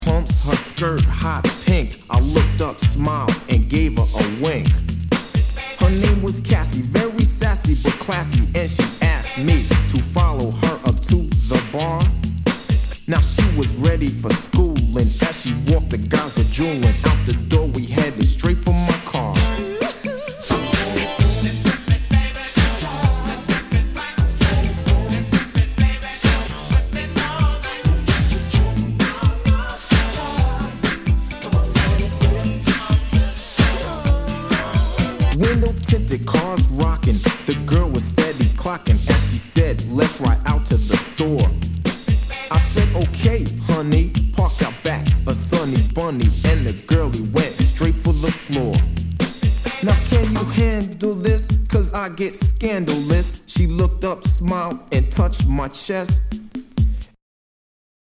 [ Genre ] HIPHOP/R&B